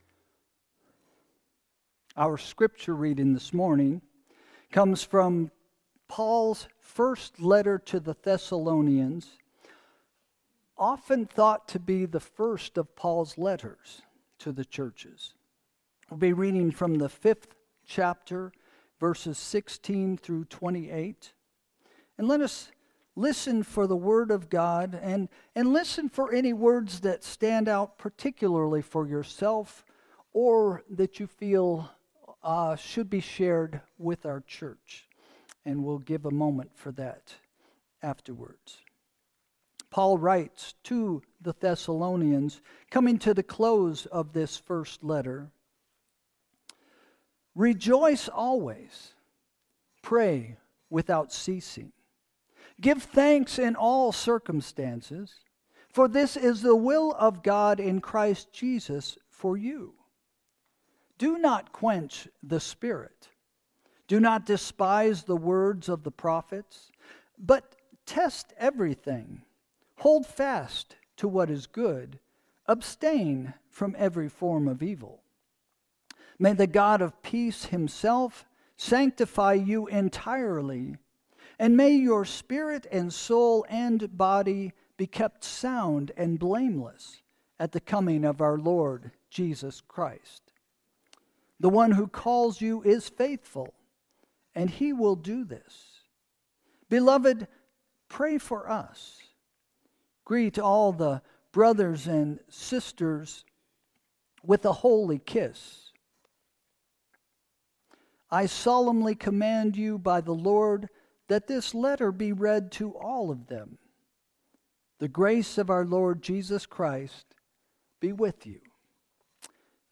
Sermon – December 14, 2025 – “Share Joy Gratefully” – First Christian Church